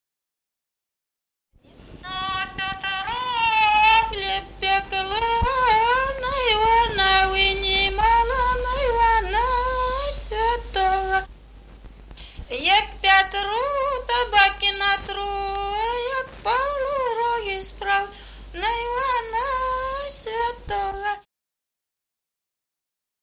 Народные песни Стародубского района «На Петра хлеб пекла», купальская.
с. Мохоновка.